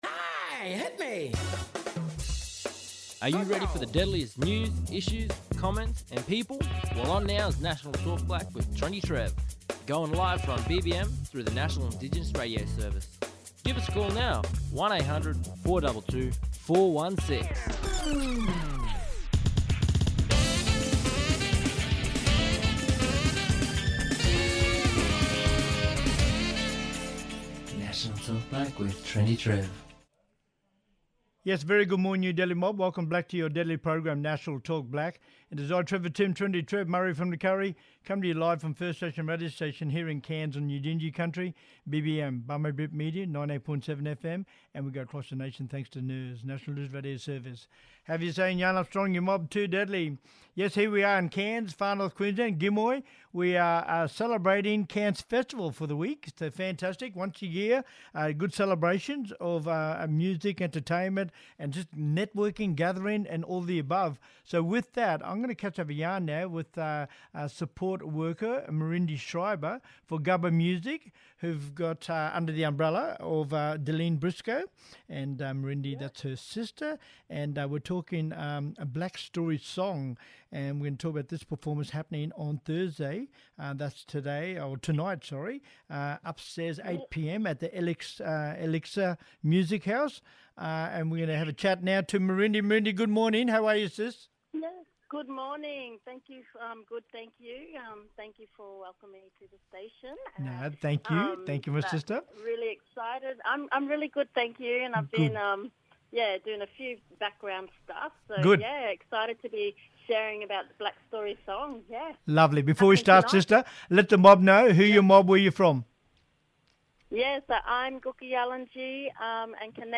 On todays National Talk Black via NIRS – National Indigenous Radio Service we have:
Musician, talking about Blak Story Song.